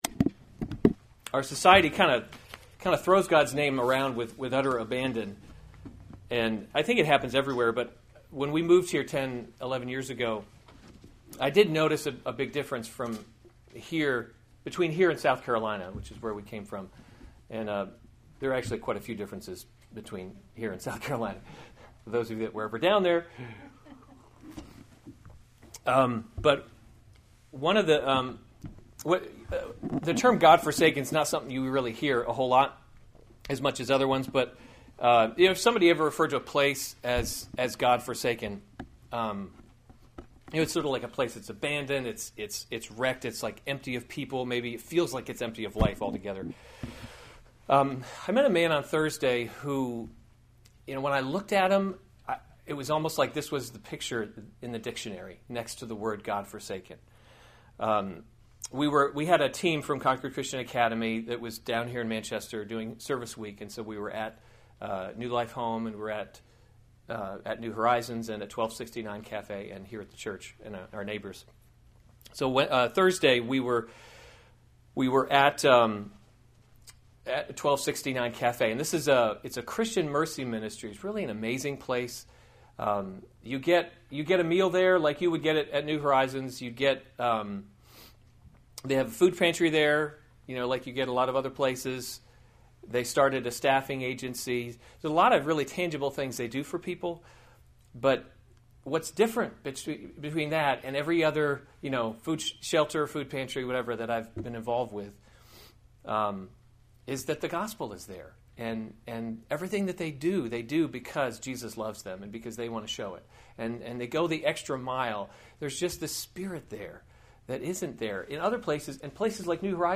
November 14, 2015 Amos: He’s Not a Tame Lion series Weekly Sunday Service Save/Download this sermon Amos 8:1-14 Other sermons from Amos The Coming Day of Bitter Mourning 8:1 This is […]